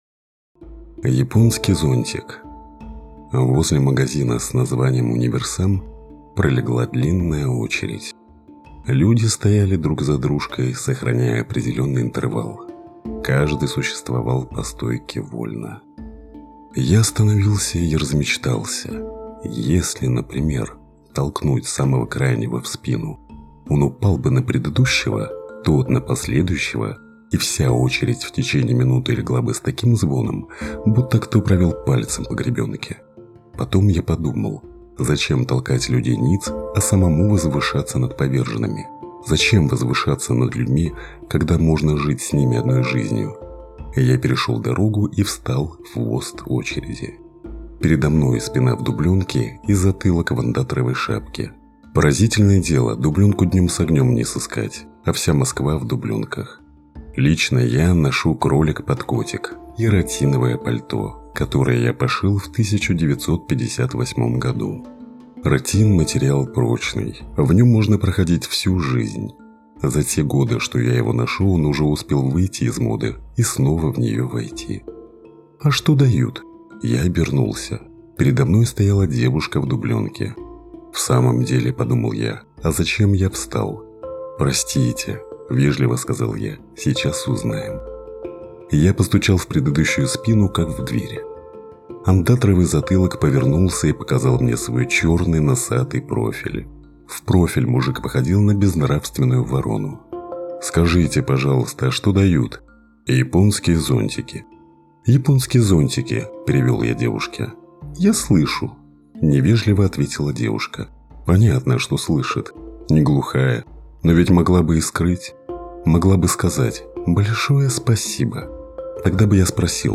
Муж, Аудиокнига/Средний